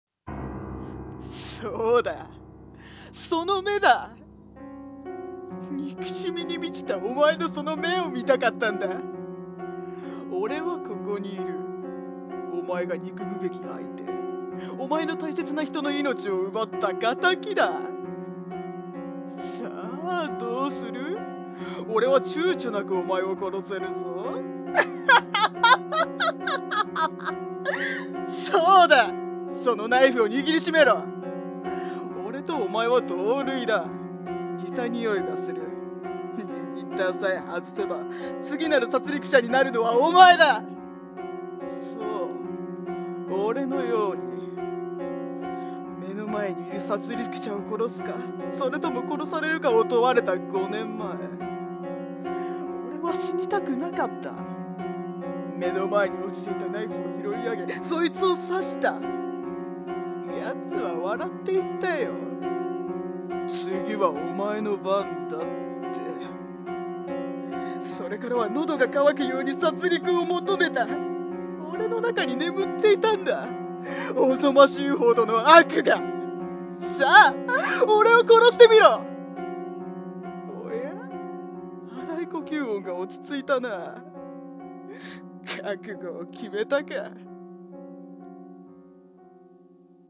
声劇【殺戮者】※悪役声劇